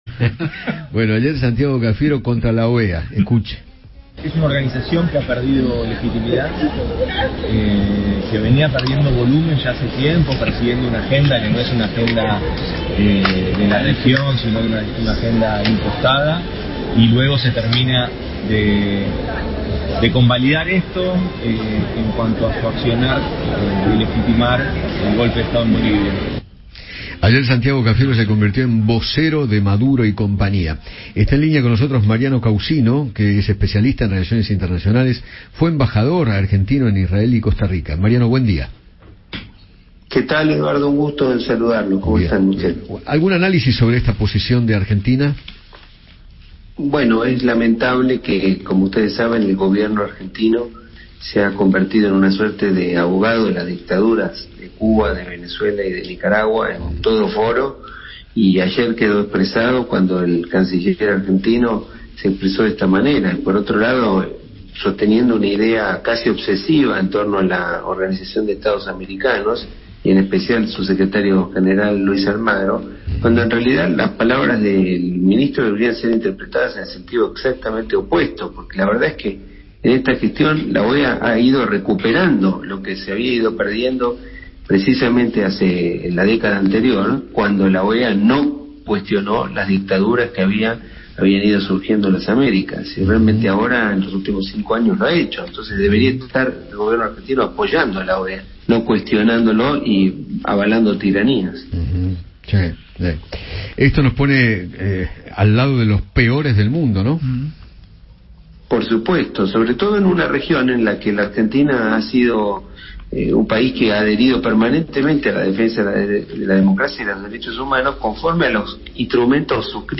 Mariano Caucino, ex embajador argentino en Israel y Costa Rica, conversó con Eduardo Feinmann sobre las declaraciones de Santiago Cafiero contra la OEA.